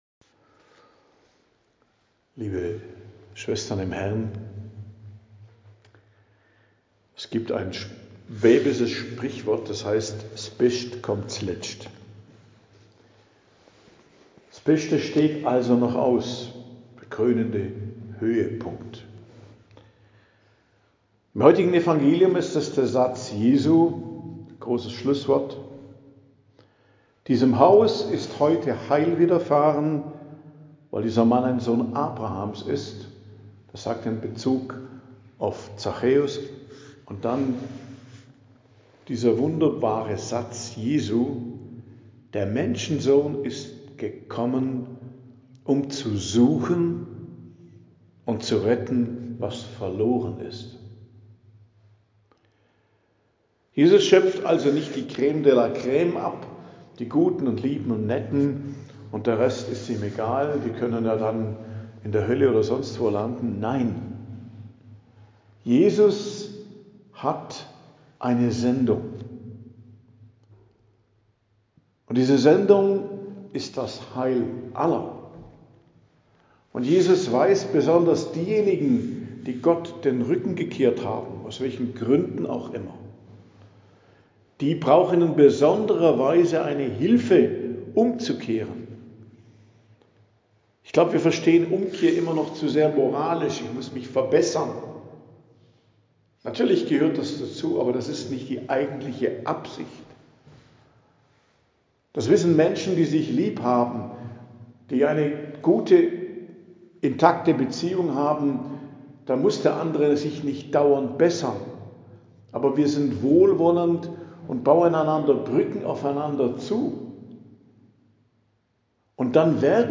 Predigt am Dienstag der 33. Woche i.J., 18.11.2025